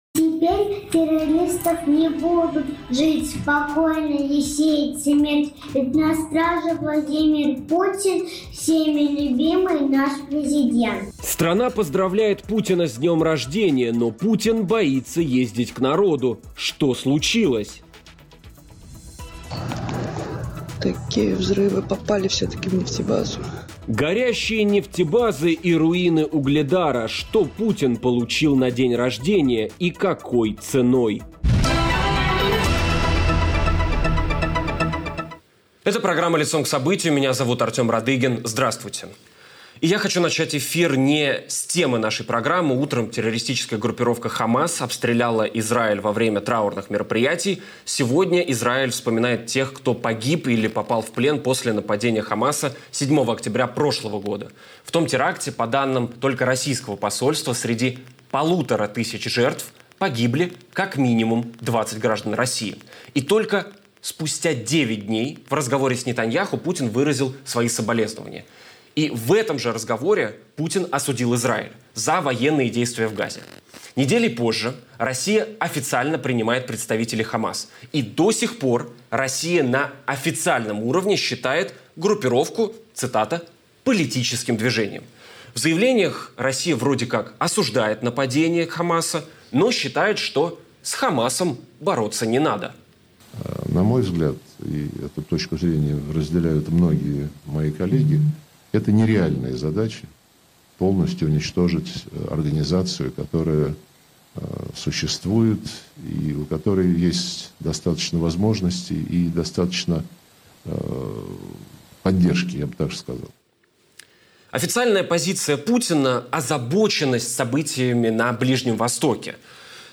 Об изоляции Путина и его страхах за жизнь поговорим в эфире программы "Лицом к событию" с блогером и юристом Марком Фейгиным.